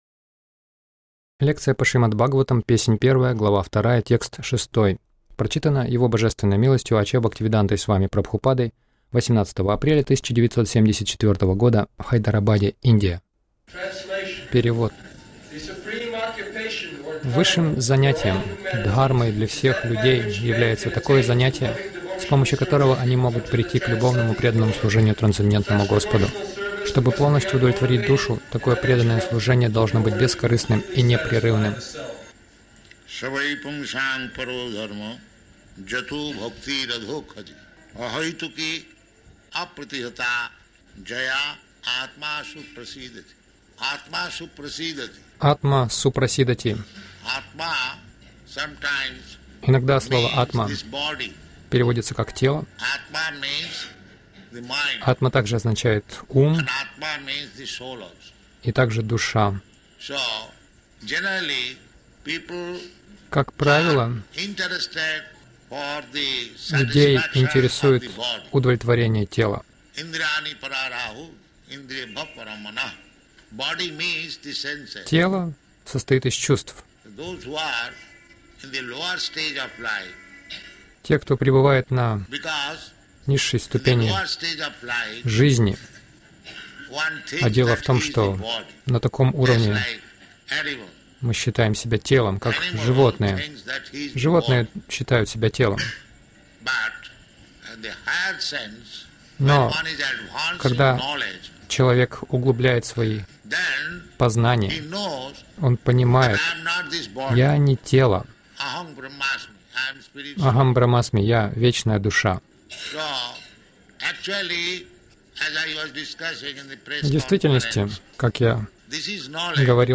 Милость Прабхупады Аудиолекции и книги 18.04.1974 Шримад Бхагаватам | Хайдарабад ШБ 01.02.06 — Очистите сердце Хари-киртаном Загрузка...